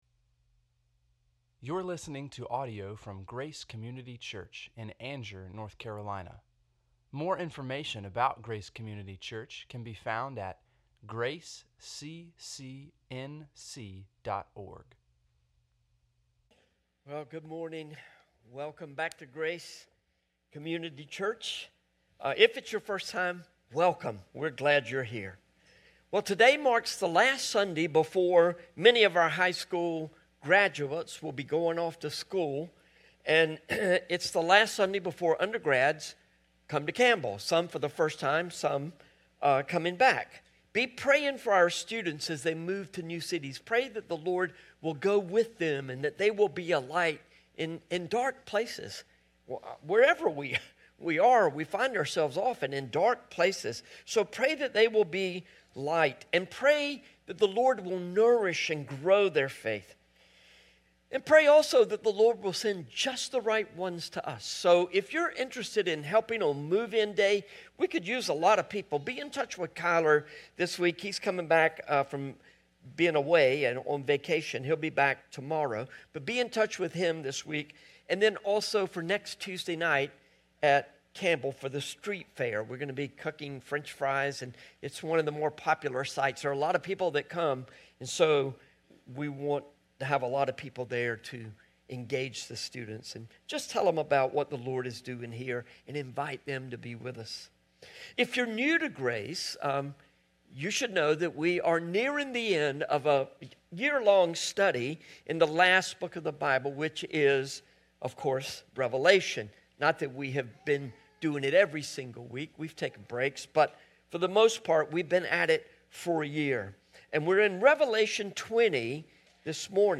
8-10-25-sermon.mp3